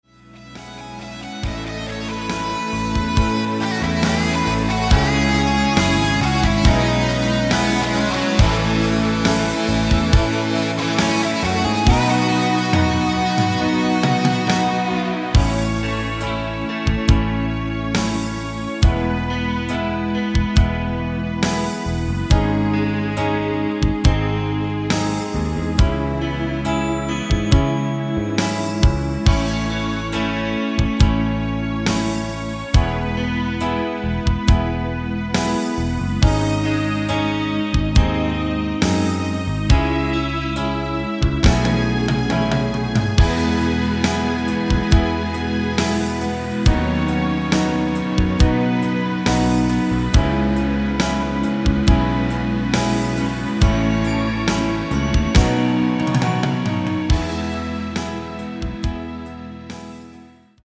앞부분30초, 뒷부분30초씩 편집해서 올려 드리고 있습니다.
중간에 음이 끈어지고 다시 나오는 이유는
위처럼 미리듣기를 만들어서 그렇습니다.